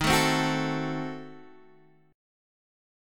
Ebdim7 chord